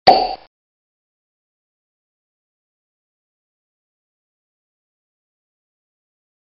Звуки пробки бутылки
Короткий звук открытия винной пробки